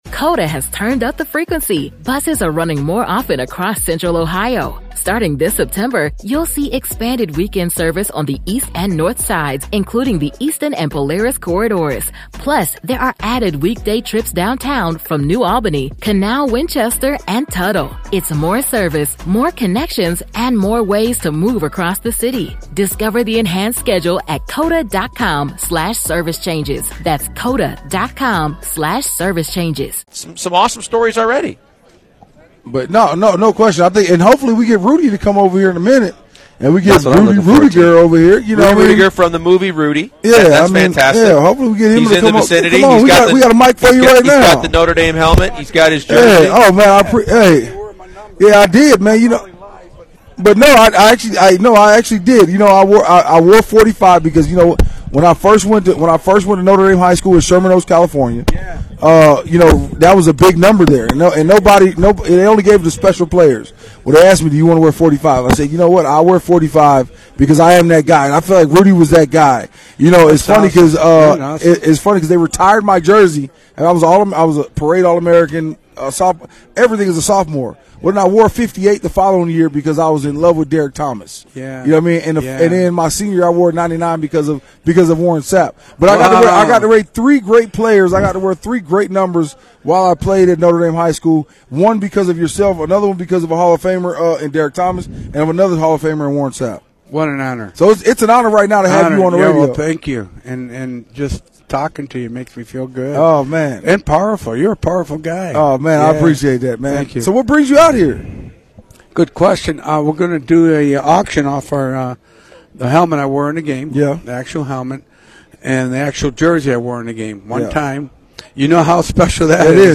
The real Rudy Reuttiger that the film "Rudy" is based off of joins the show live from Radio Row in Houston.